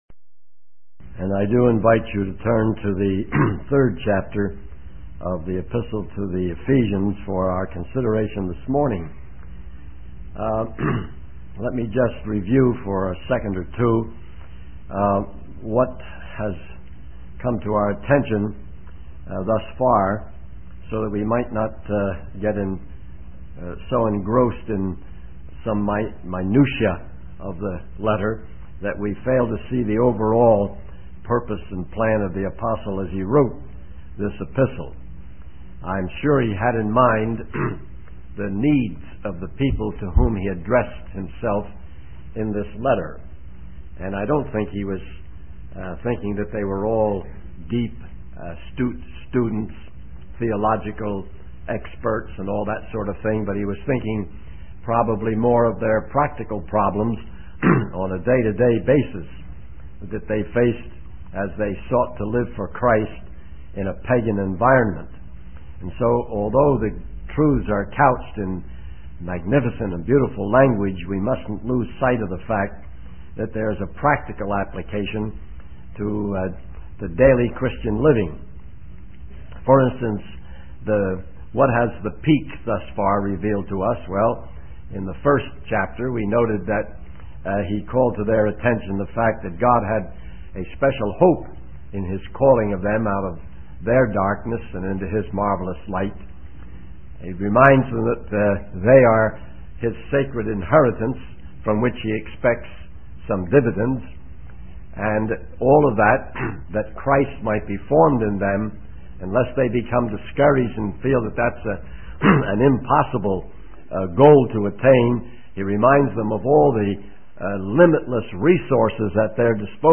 In this sermon, the speaker reflects on his experience at Camp Blanding and relates it to the Christian life. He emphasizes the importance of understanding God's purpose and plan in the universe and how we fit into it.